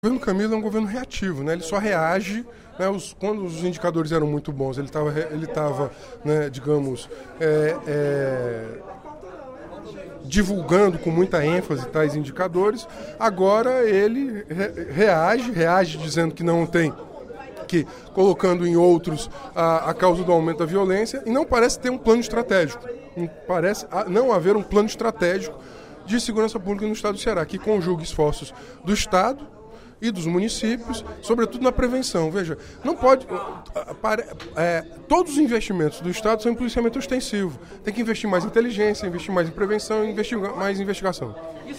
O deputado Renato Roseno (Psol) solicitou, durante o primeiro expediente da sessão plenária desta terça-feira (11/07), a elaboração de um Plano Estadual de Segurança - pelo Governo do Estado - para combater o aumento da violência.